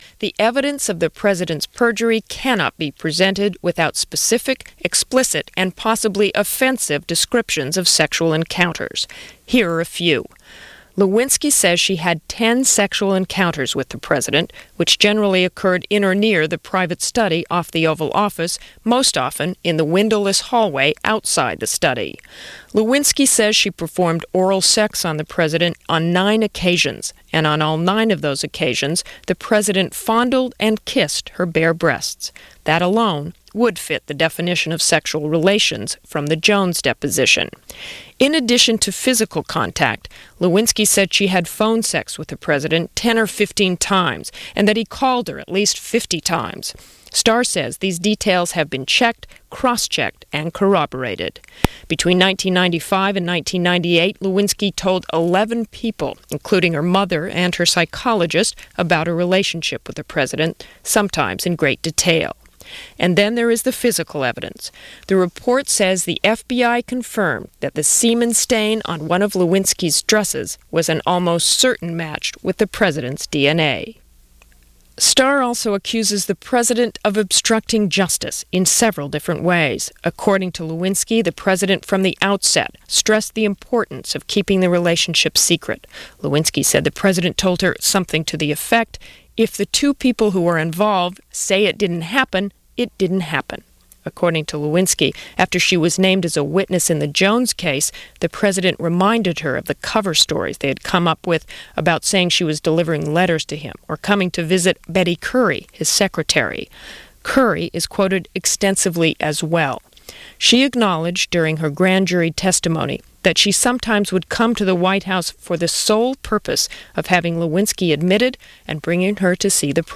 – The Starr Report – Commentary and review – November 19, 1998 – National Public Radio – Gordon Skene Sound Collection –